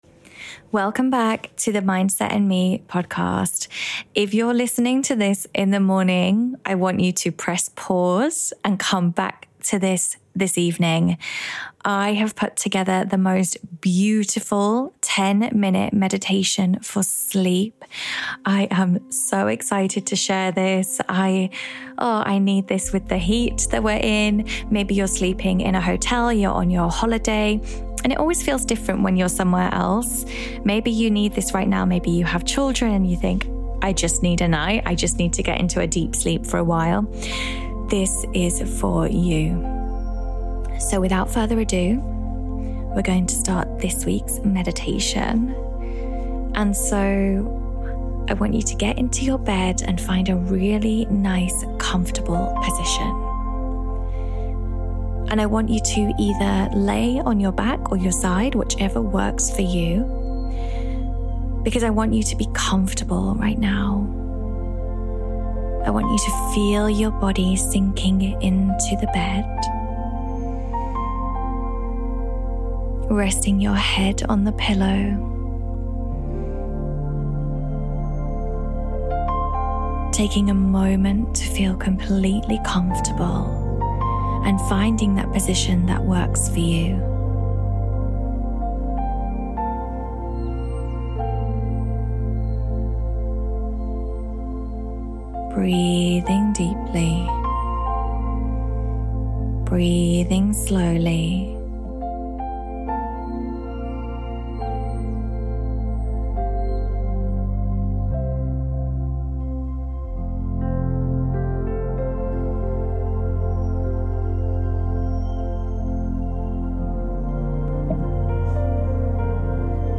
Learn to Meditate Series | Sleep Meditation